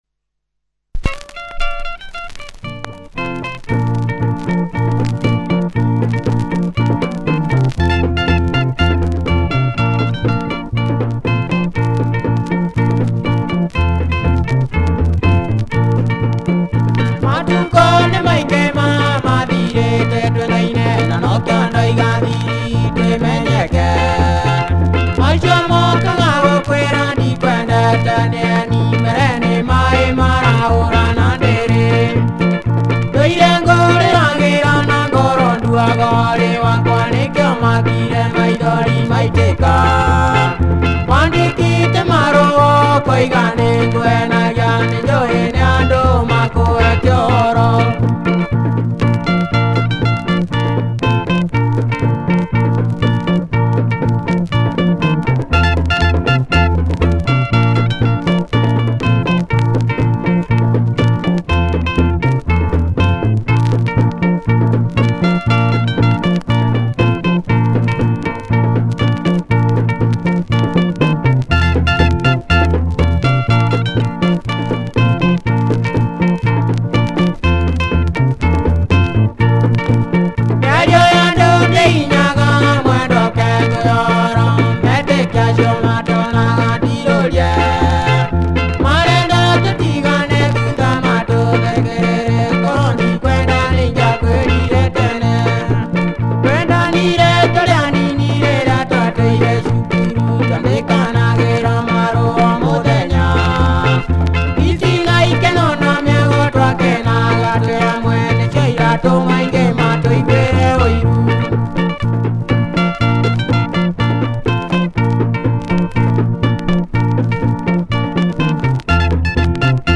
Traditional Kikuyu sounds